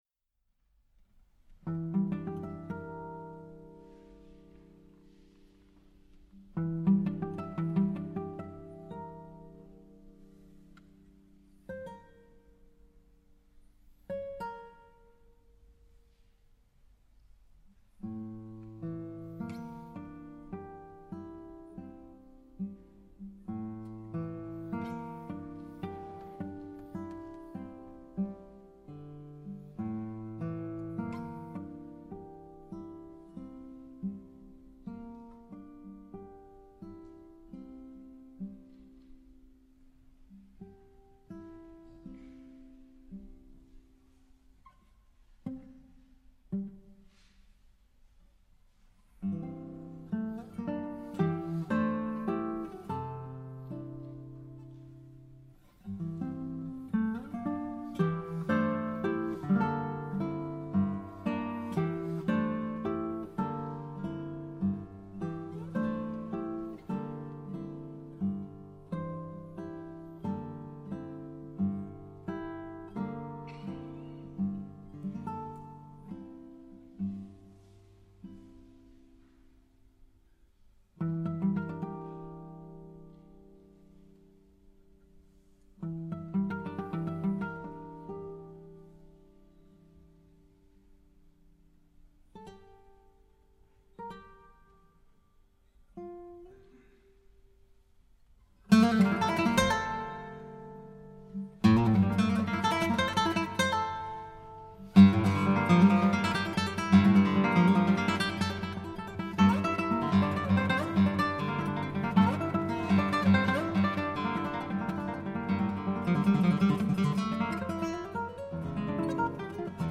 for guitar solo